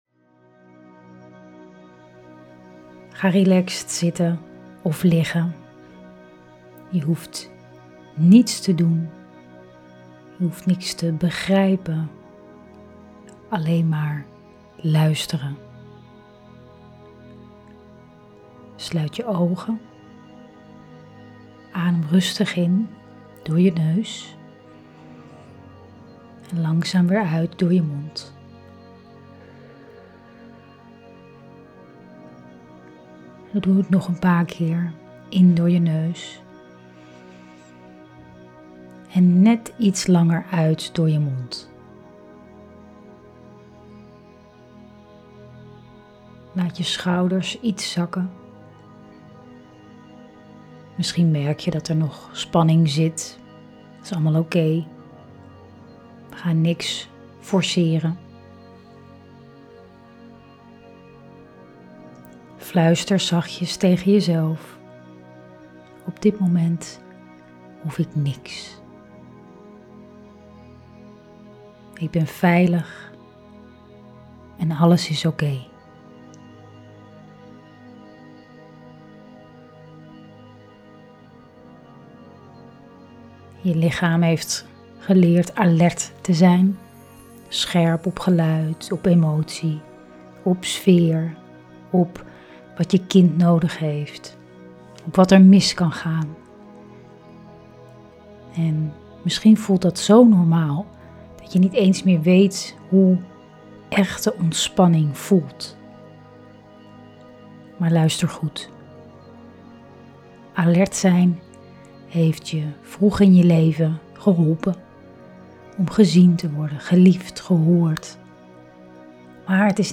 Voor-diepe-ontspanning.mp3